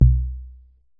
deep tom.mp3